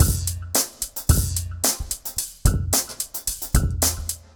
RemixedDrums_110BPM_17.wav